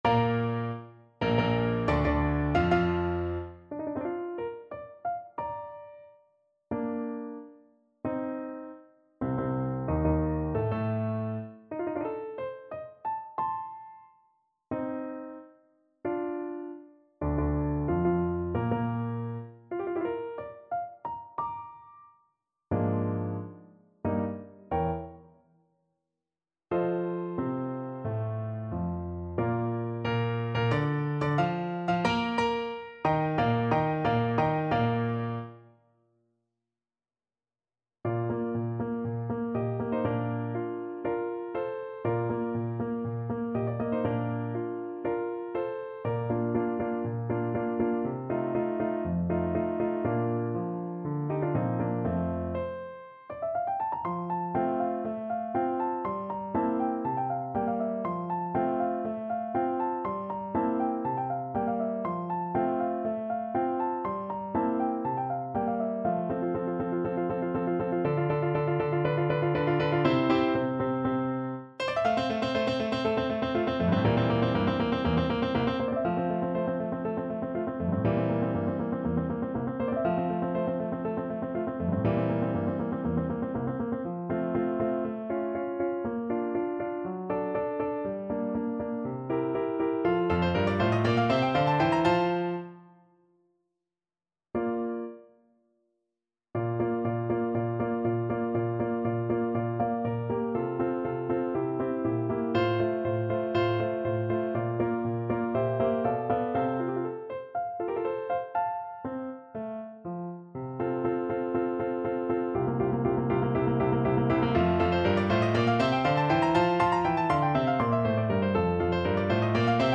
4/4 (View more 4/4 Music)
Andante maestoso =90
Classical (View more Classical Soprano Voice Music)